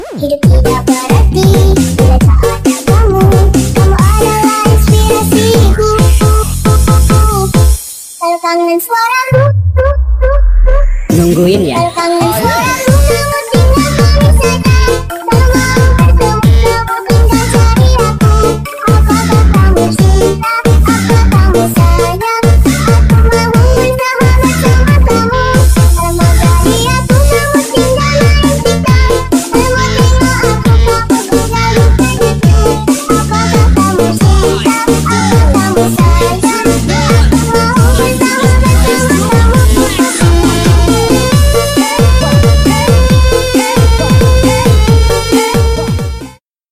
Thể loại nhạc chuông: Nhạc Tik Tok